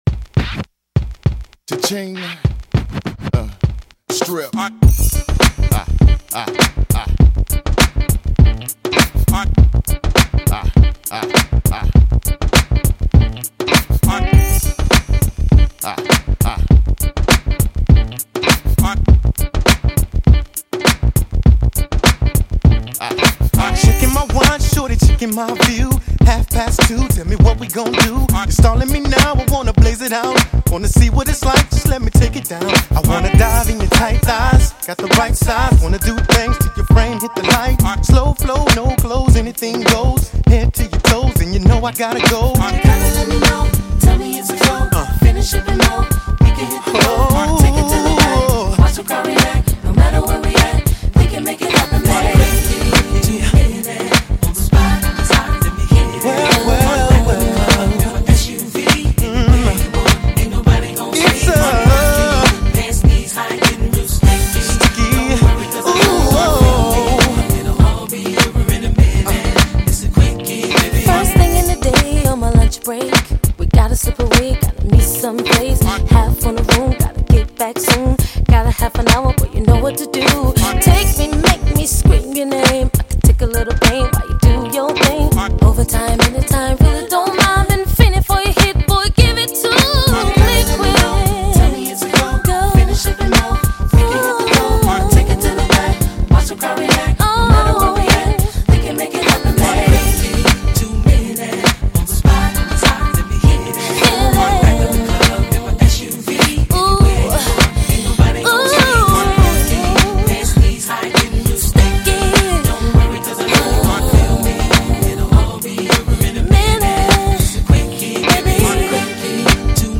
A duo act that defined neo-soul